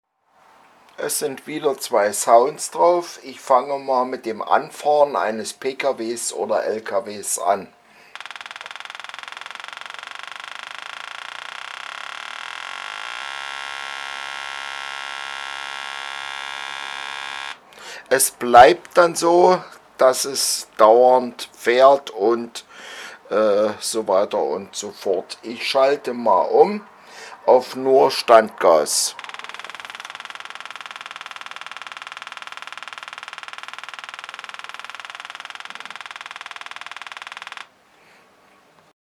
2-Anfahren+Standgas
2-Anfahren+Standgas.mp3